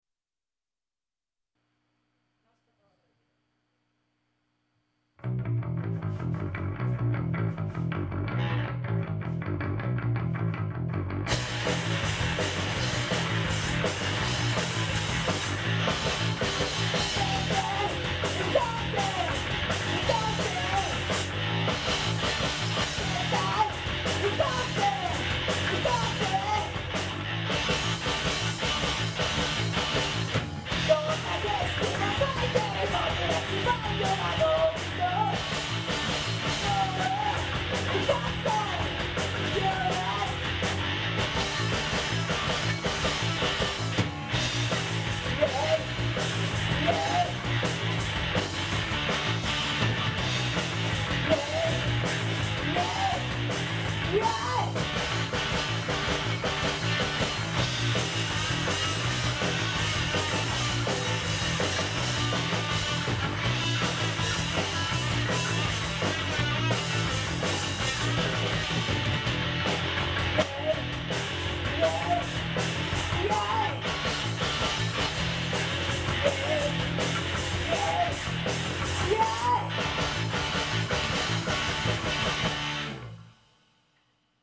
(demo)